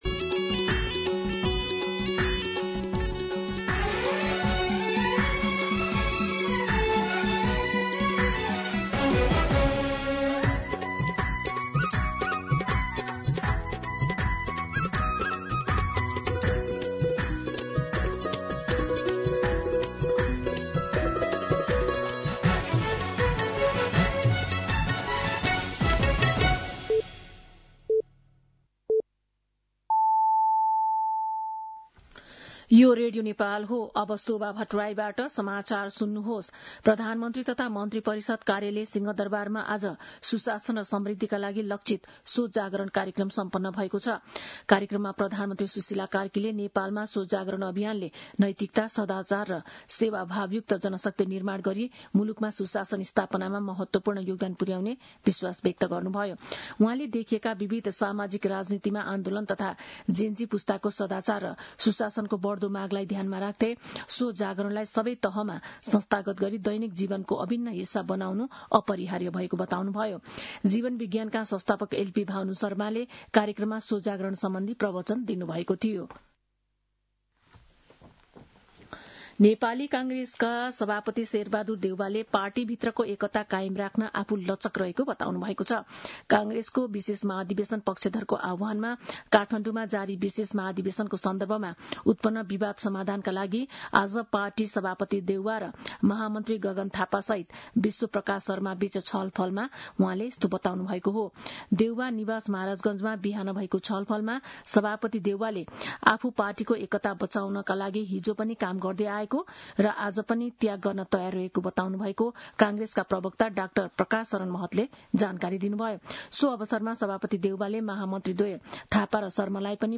दिउँसो १ बजेको नेपाली समाचार : ३० पुष , २०८२
1-pm-Nepali-News-5.mp3